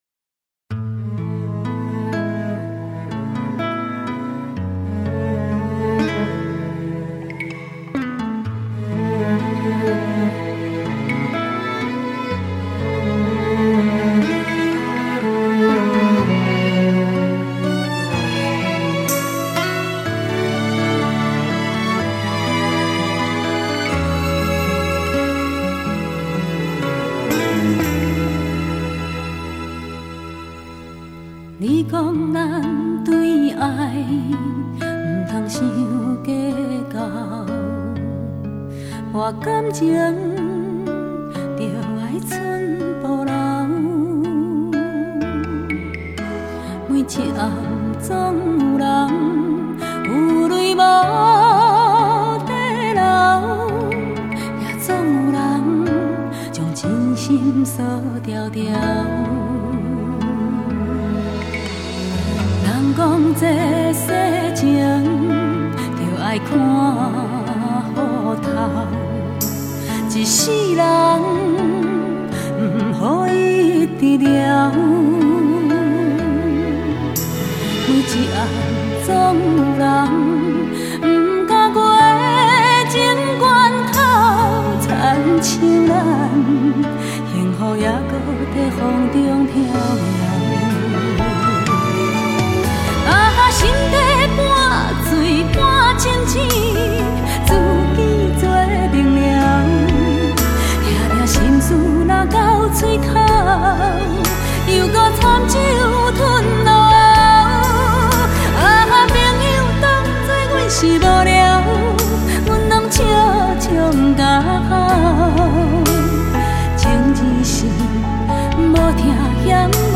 ★ 前所未見之優異音效，展現一代歌后最完美的嗓音！